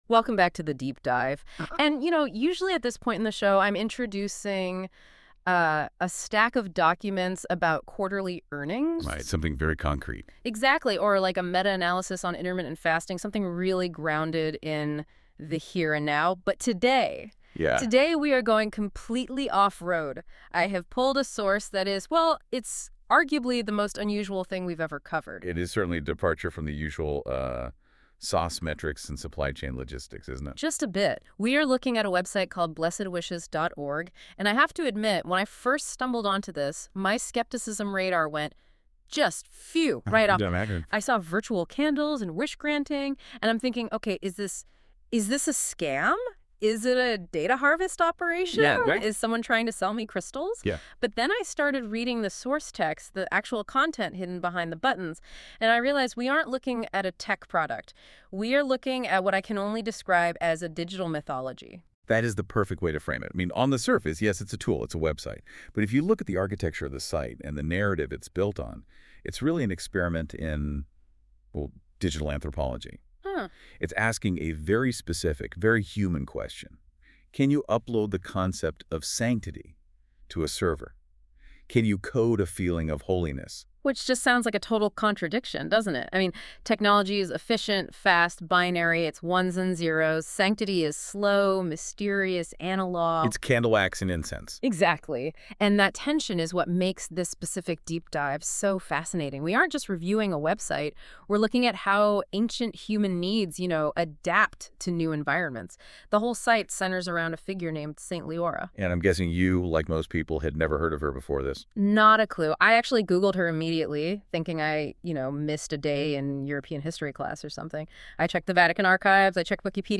Radio Review Click here to hear this 18-minute discussion.
radio-interview-1OP2CfpvjInTR890.mp3